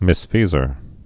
(mĭs-fēzər)